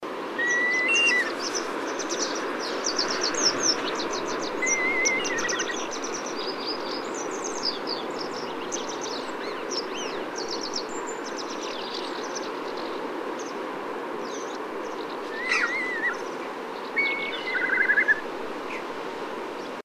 birdsounds